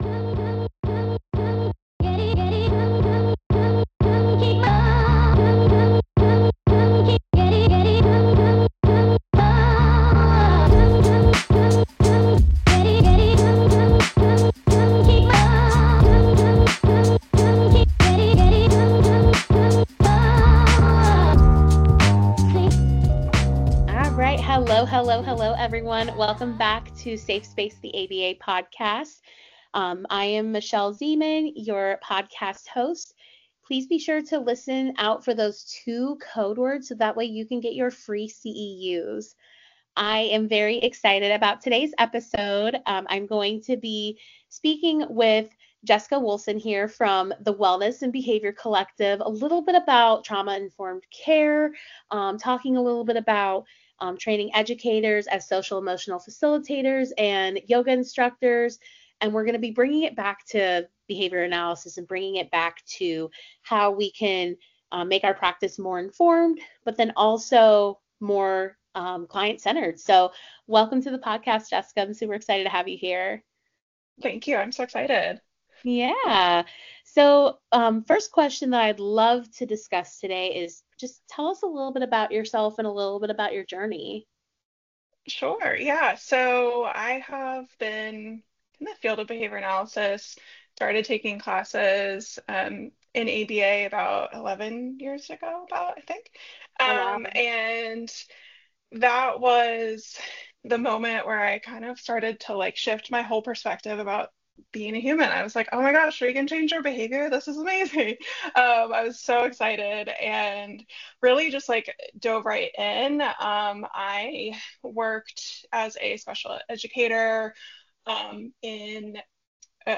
They discuss the biggest challenges teachers face, strategies for balancing academic and behavioral needs, and how behavioral science can enhance student outcomes. From test scores to classroom engagement, inclusive education to cost-effective interventions, this conversation dives deep into the practical application of ABA in schools.